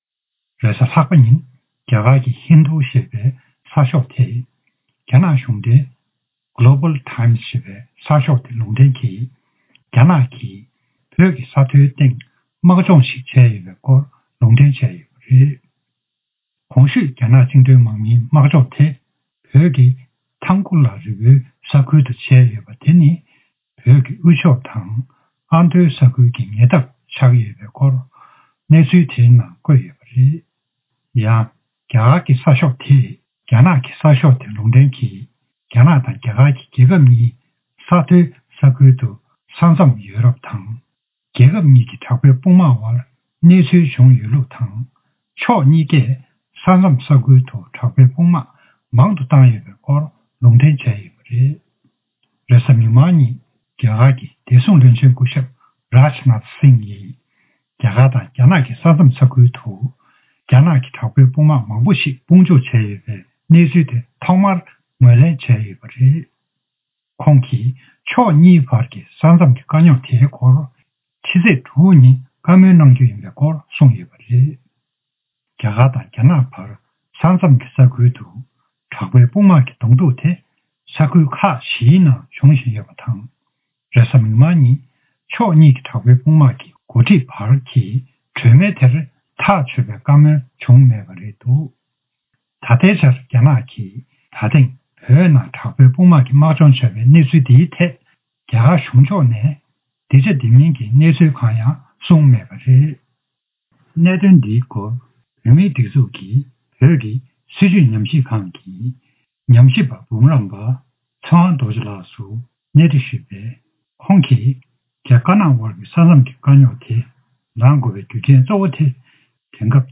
བཀའ་འདྲི་ཞུས་པ།
སྒྲ་ལྡན་གསར་འགྱུར། སྒྲ་ཕབ་ལེན།